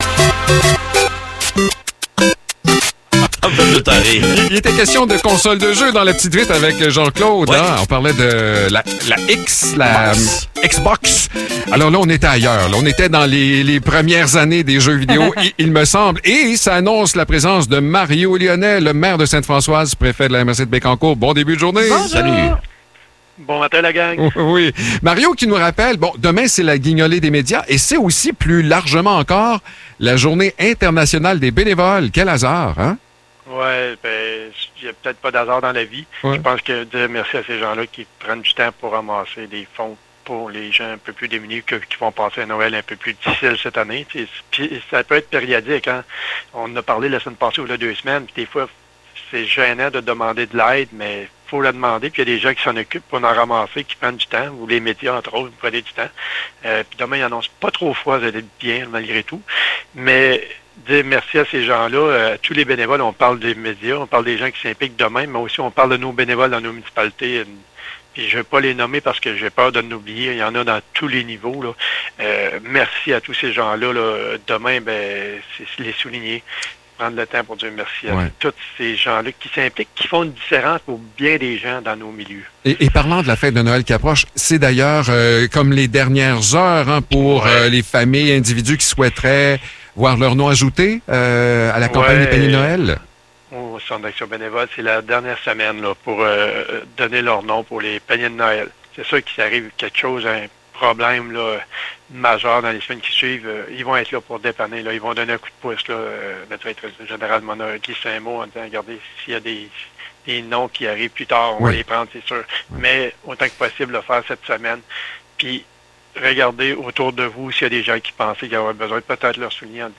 Échange avec Mario Lyonnais
Mario Lyonnais est maire de Ste-Françoise et préfet de la MRC de Bécancour. Il nous invite à donner généreusement à la Guignolée des médias demain et surtout, de ne pas hésiter à demander de l’aide.